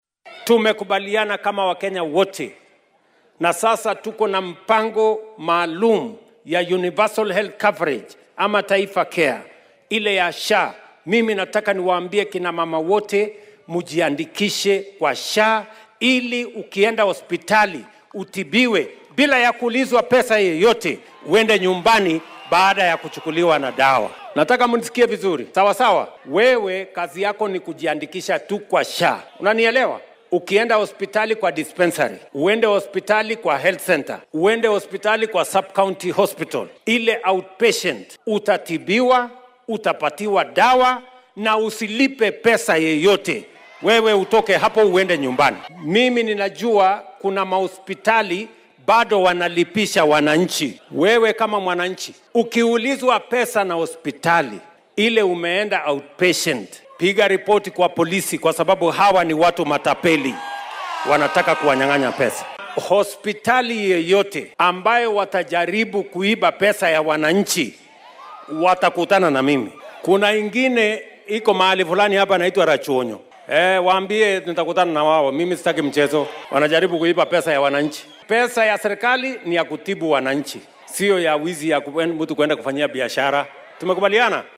Isagoo ka hadlayay munaasabad lagu xoojinayay haweenka oo ka dhacday ismaamulka Homa Bay, Madaxweynaha ayaa shaaca ka qaaday in isbitaal kasta oo lagu helo isagoo lacago baad ah ka qaadanaya muwaadiniinta uu la kulmi doono cawaaqib degdeg ah.